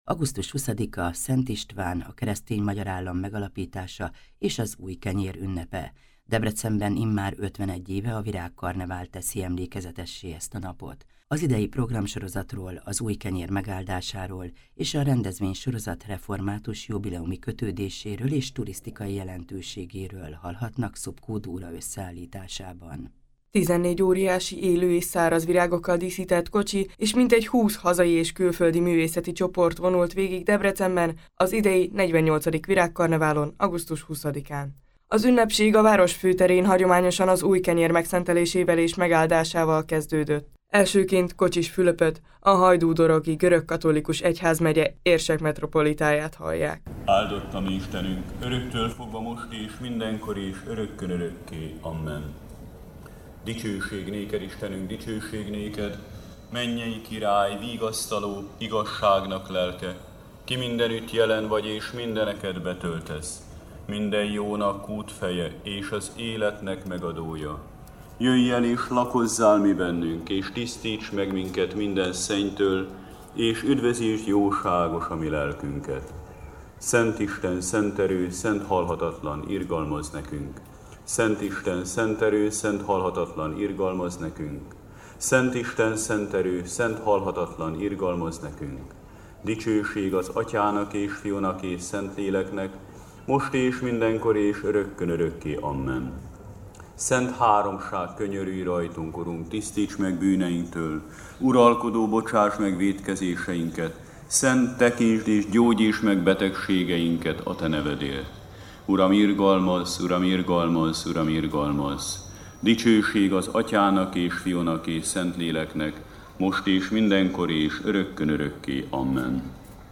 Prédikáló viráginstalláció a Debreceni Virágkarneválon - hanganyaggal